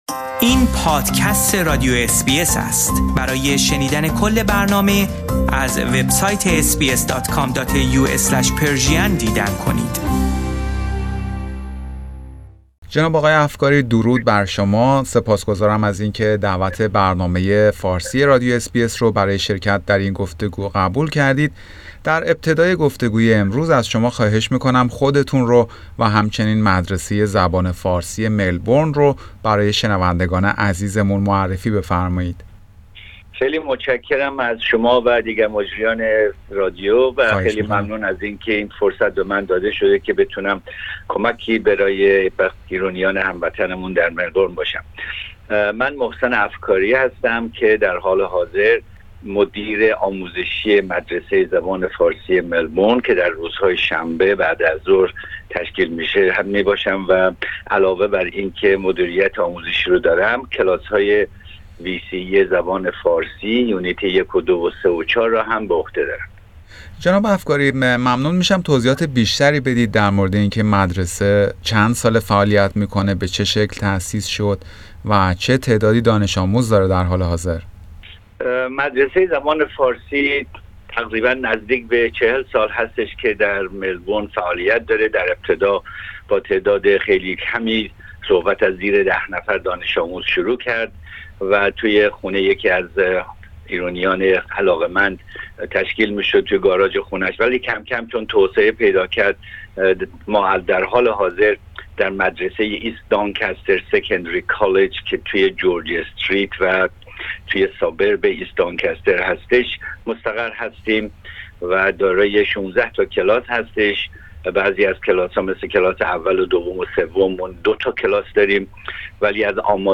در همین خصوص گفتگویی داشتیم